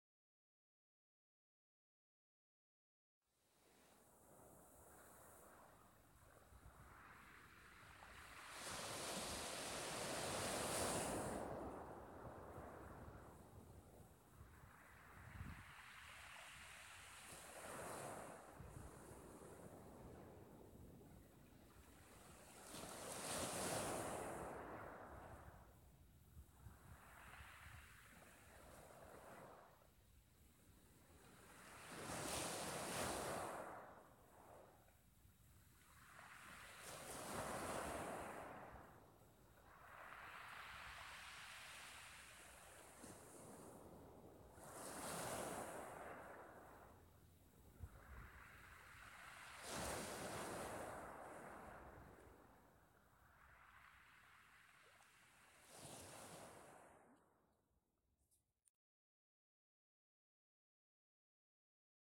海浪起伏, 海風吹拂, 海浪聲陣陣傳來,
海浪聲⬇